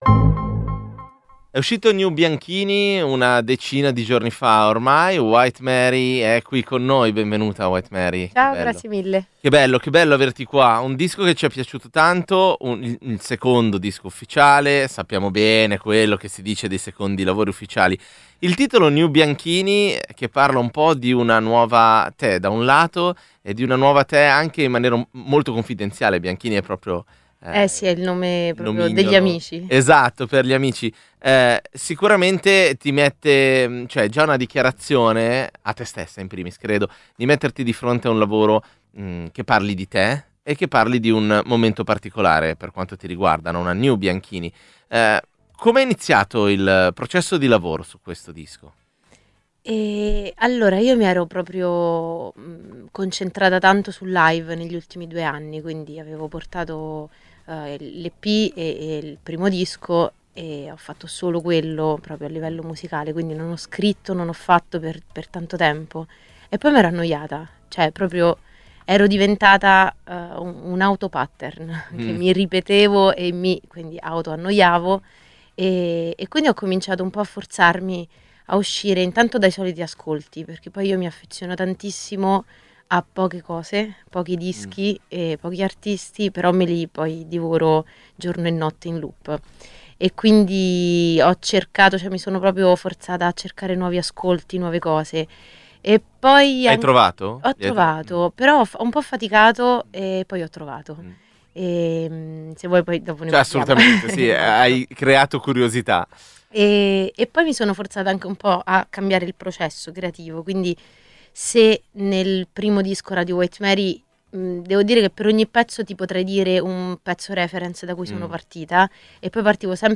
Un disco in cui cambia approccio, partendo dalla scrittura per arrivare pian piano alla produzione, tessendo le trame elettroniche su cui poggia il lavoro. Riascolta l'intervista